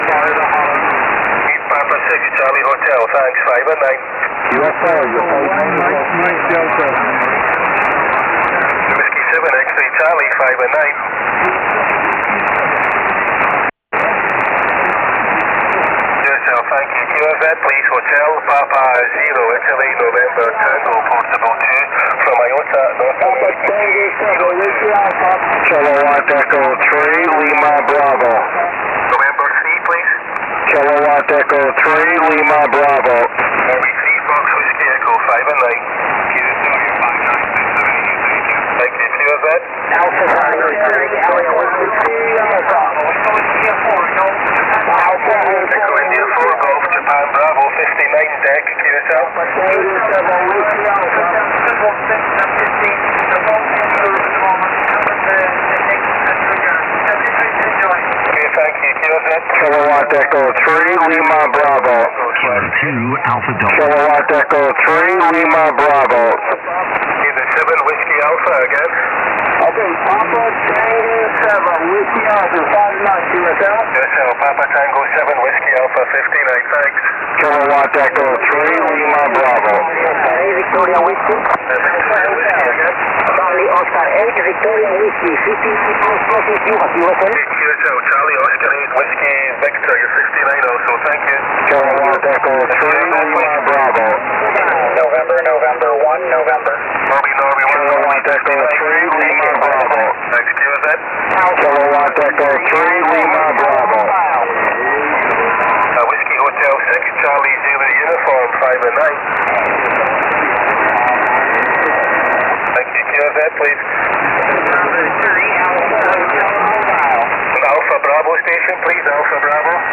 running an SSB pileup from Panama (simplex)